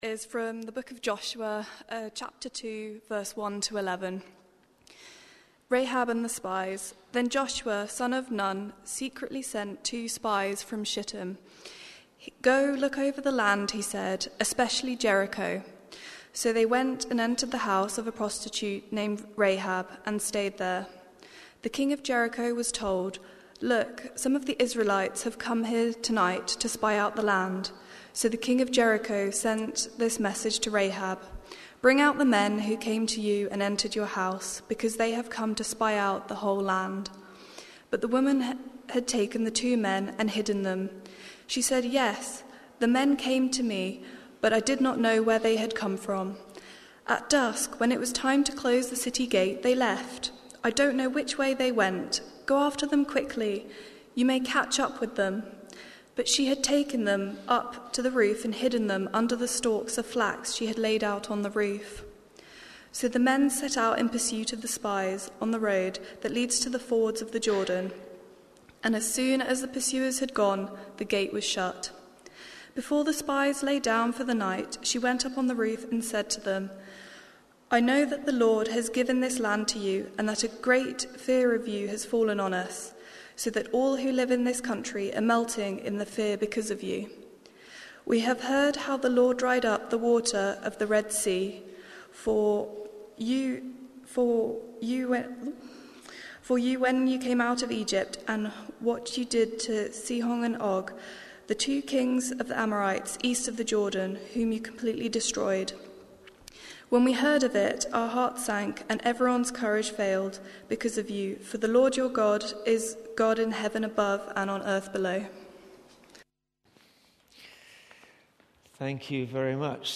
Watch Listen play pause mute unmute Download MP3 Thanks for joining us this morning as we come together both online and in-person to worship! We're continuing our new series today looking at some of the lessons we can learn from the life of Joshua.